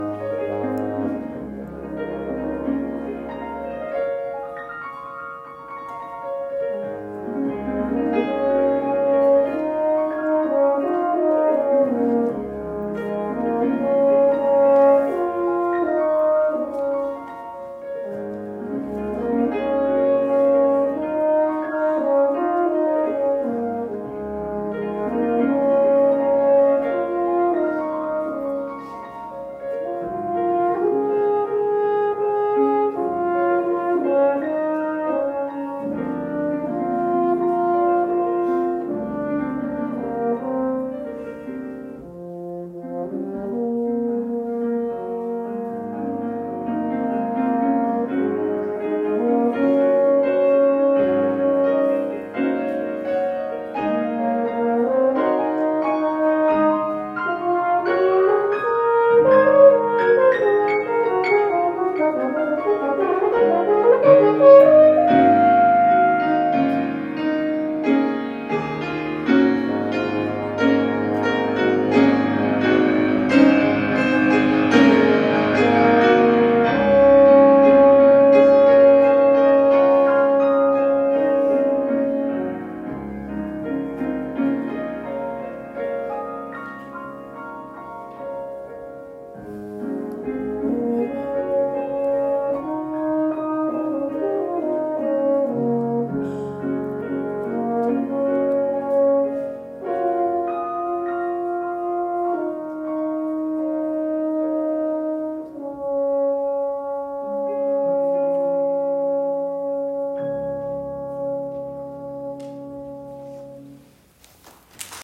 Horn in F and Piano
Arranged for Horn in F and Piano.